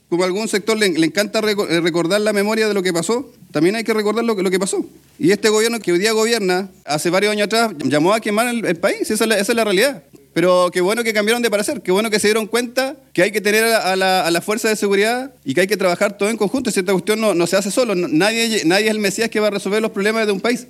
Más de una vez lo planteó el consejero del Partido de la Gente, Cristian Vargas, diciendo que “este Gobierno, hace años atrás, llamó a quemar el país“.